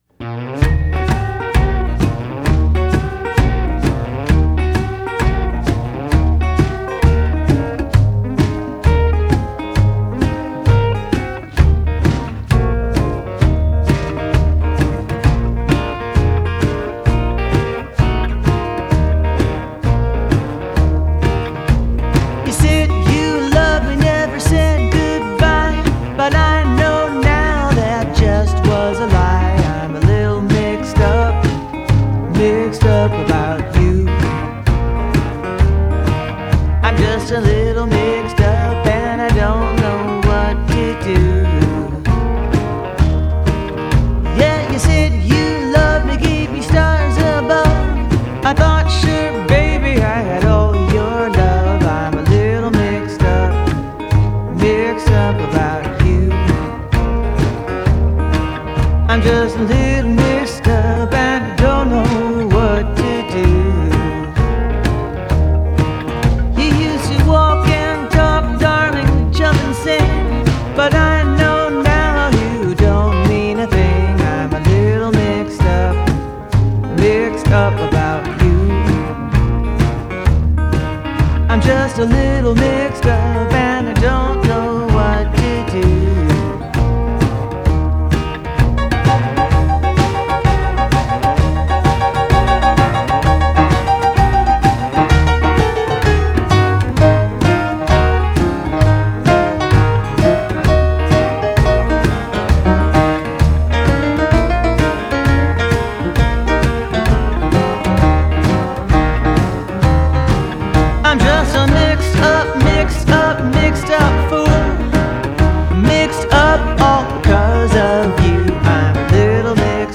Genre: Jazz, Vocal Jazz